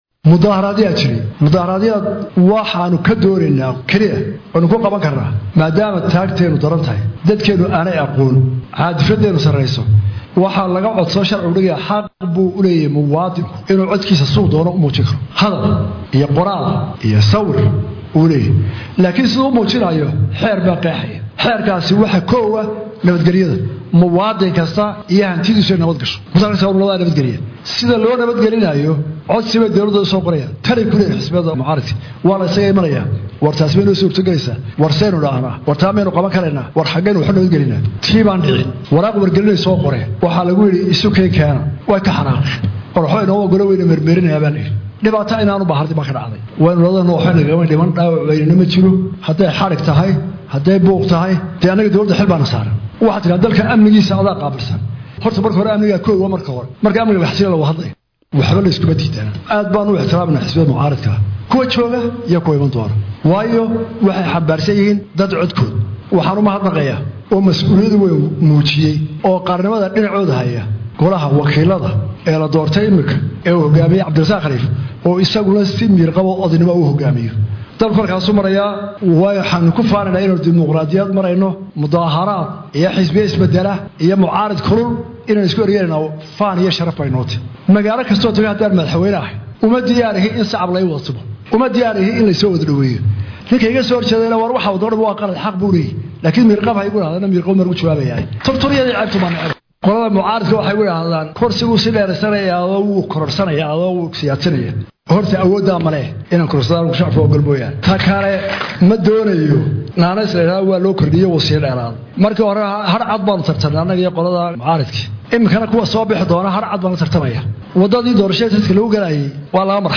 Mar uu xalay magaalada Ceerigaabo kula hadlay waxgaradka iyo madaxda dhaqanka gobolka Sanaag, ayaa Madaxweynhuhu waxa uu sheegay inuu ka xun yahay dhibaatada ka dhalatay dibedbixii 9-kii June ka dhacay Hargeysa.
Halkan ka Dhageyso Codka Madaxweyne Muuse Biixi oo dhammeystiran: